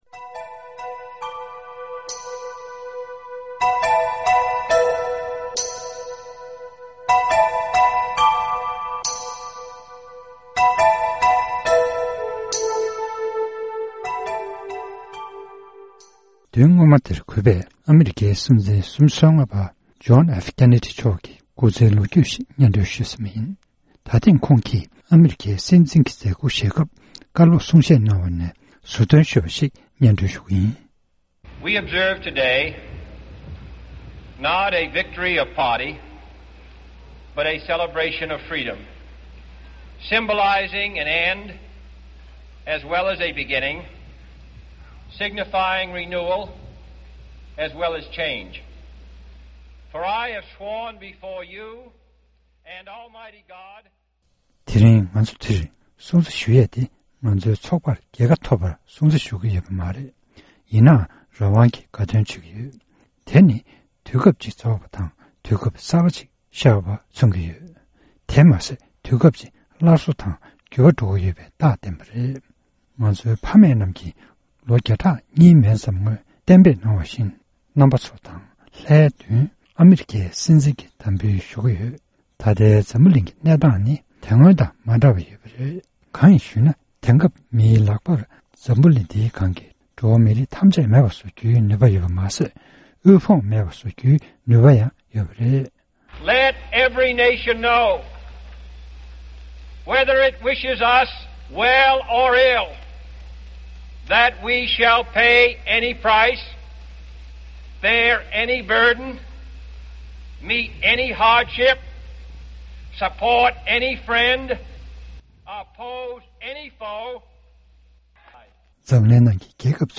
ཀེ་ནེ་ཌིའི་དམ་འབུལ་མཛད་སྒོའི་གསུང་བཤད།